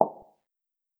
click-close.wav